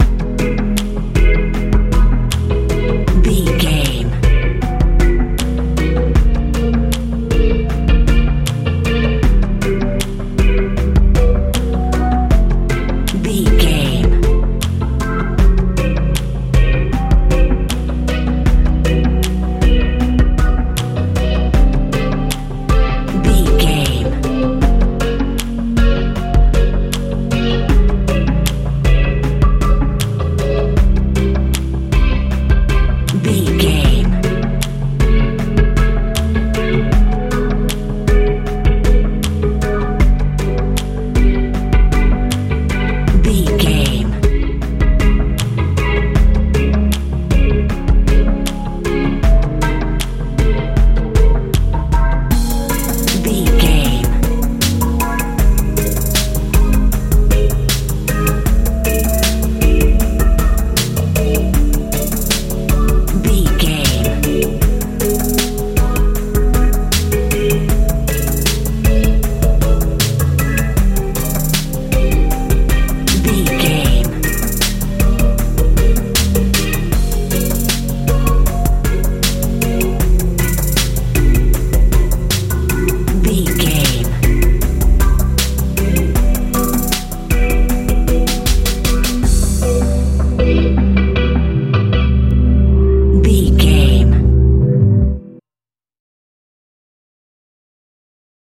hip hop feel
Ionian/Major
cool
magical
bass guitar
drums
synthesiser
80s
90s
strange